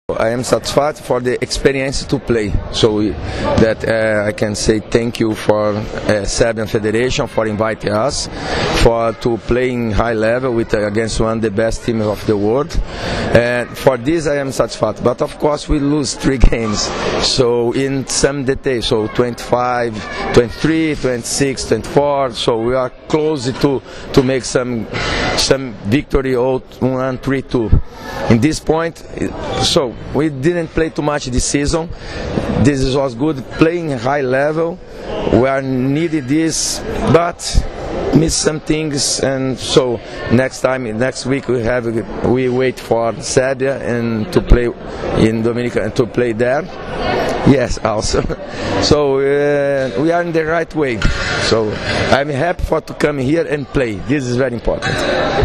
IZJAVA ANĐELA VERĆEZEA